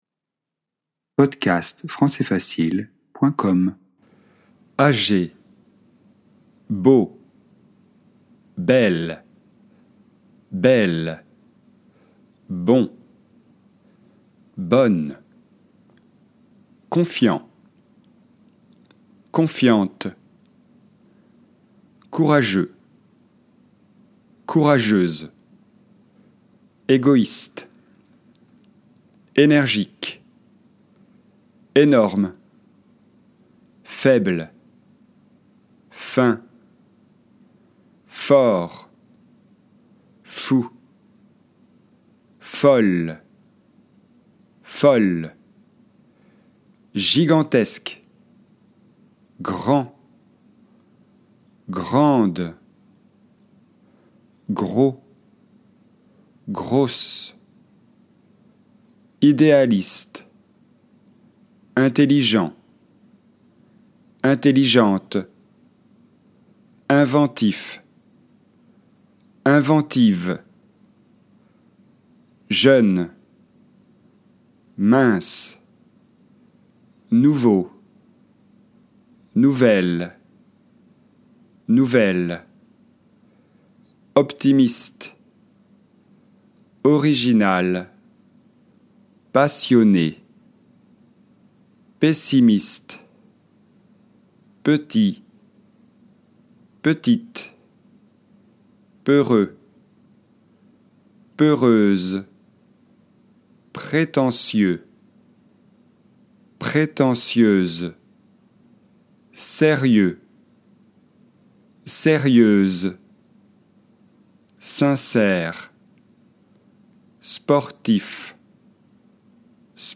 françaisprononciationanglais
âgé (e)[ɑʒe]eldery